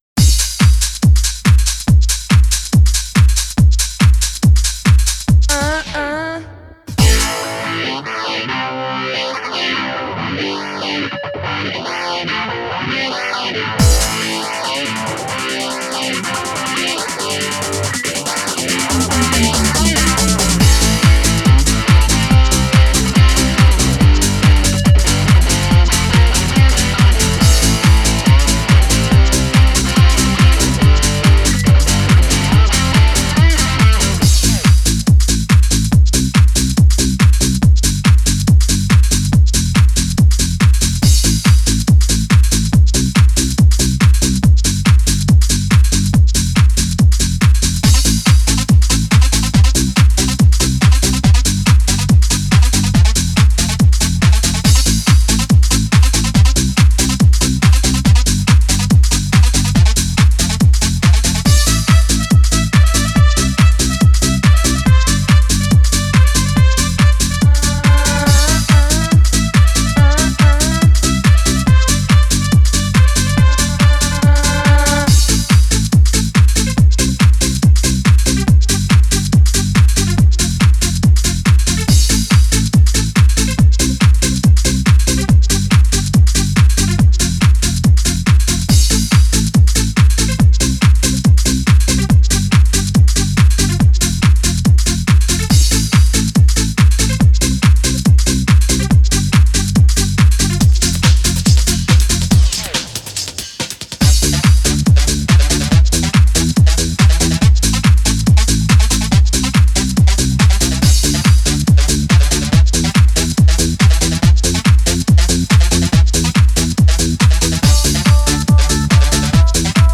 Трек размещён в разделе Русские песни / Танцевальная.